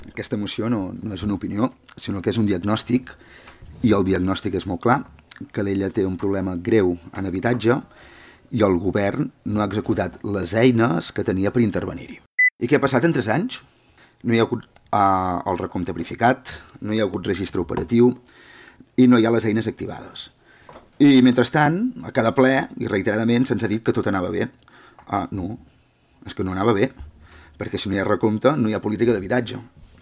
El ple municipal de Calella va viure un debat intens sobre la situació de l’habitatge arran d’una moció presentada per la CUP per completar el recompte d’habitatges buits i activar mesures municipals d’intervenció.
El portaveu del partit, Albert Comas, va obrir el debat defensant que la moció parteix d’una diagnosi clara sobre la situació de l’habitatge a la ciutat.